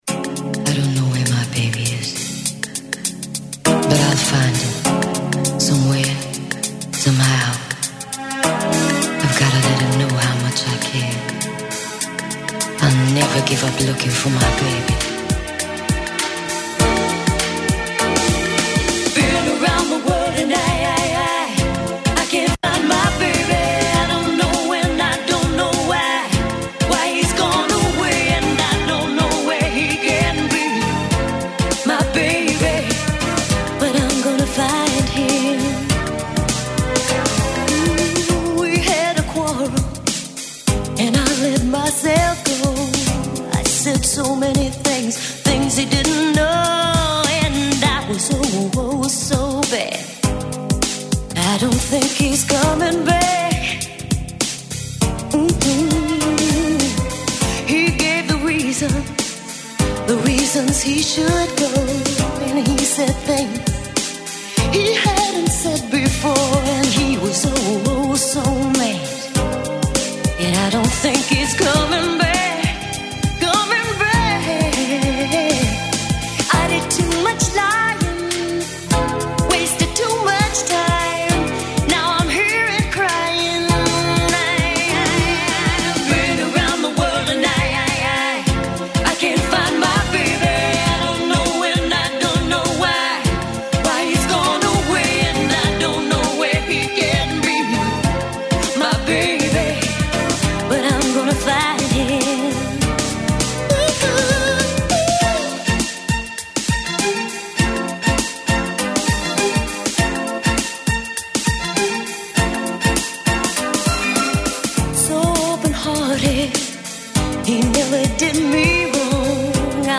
Ακούστε την εκπομπή του Νίκου Χατζηνικολάου στον ραδιοφωνικό σταθμό RealFm 97,8, την Τρίτη 17 Ιουνίου 2025.